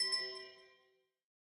Minecraft Version Minecraft Version 1.21.5 Latest Release | Latest Snapshot 1.21.5 / assets / minecraft / sounds / block / amethyst / resonate4.ogg Compare With Compare With Latest Release | Latest Snapshot
resonate4.ogg